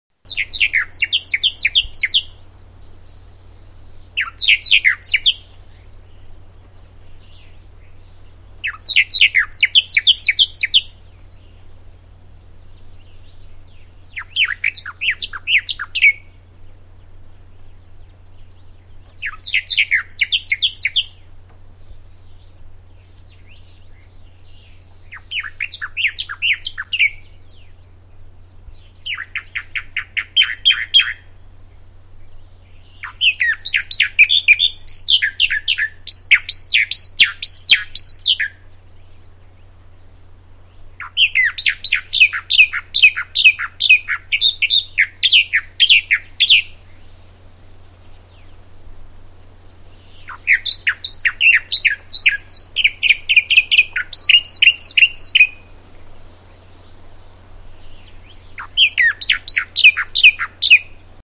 Bahamas Mockingbird
Category: Animals/Nature   Right: Personal